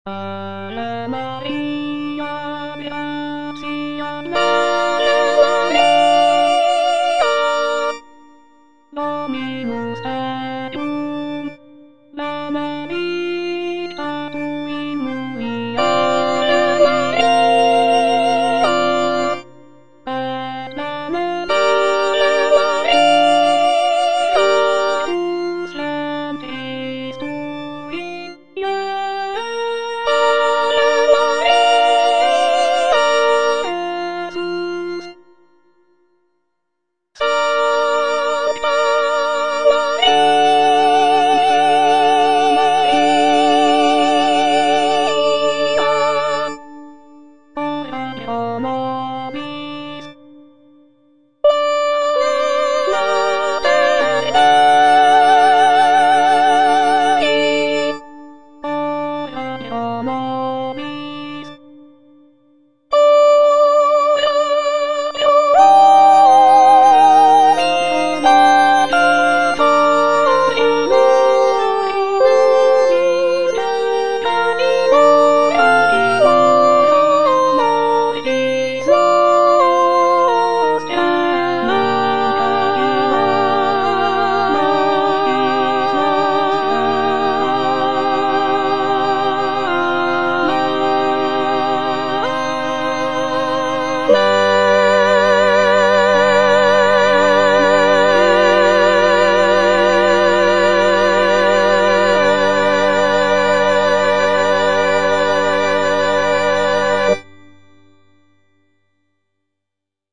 Soprano (Emphasised voice and other voices) Ads stop
a choral work based on a traditional Latin text